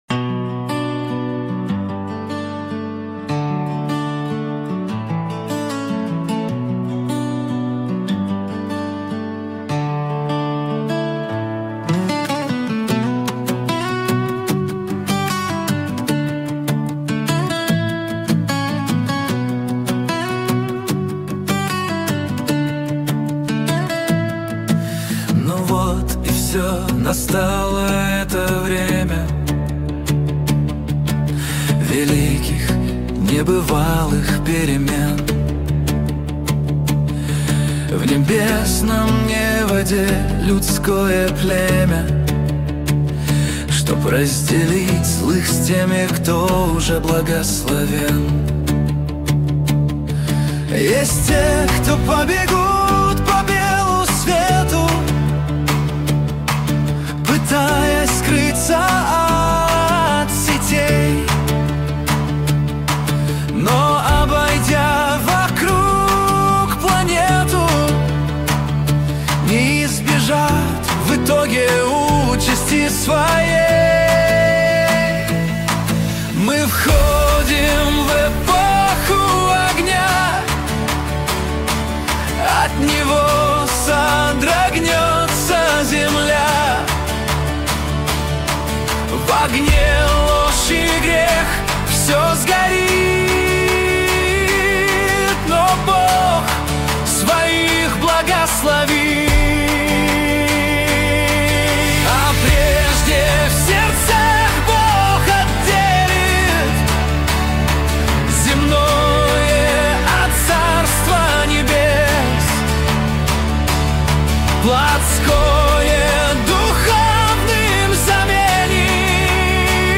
песня ai
138 просмотров 531 прослушиваний 55 скачиваний BPM: 150